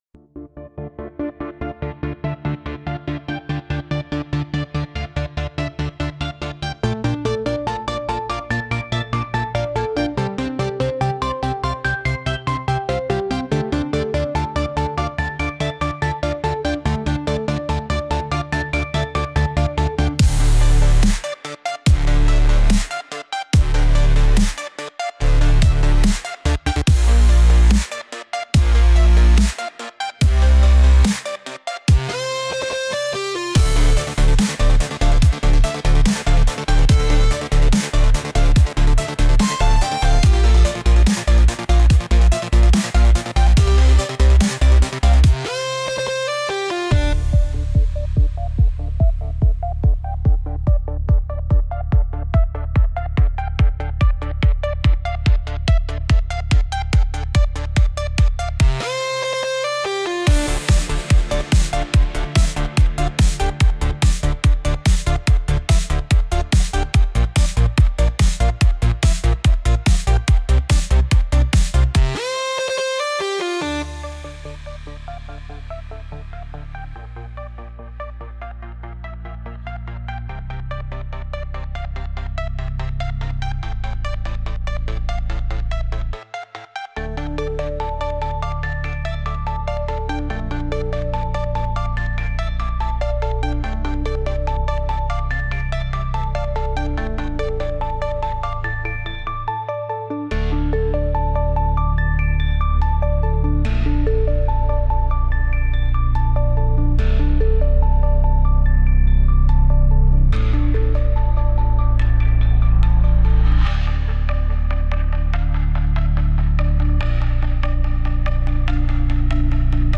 Genre: Electronic Mood: Upbeat Editor's Choice